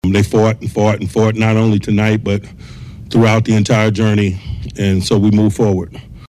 Coach Mike Tomlin says the Steelers battled for the win.
nws0580-mike-t-we-fought.mp3